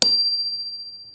question_markTermékkör Pengetős csengő
23 mm átmérőjű csengő műanyag pengetővel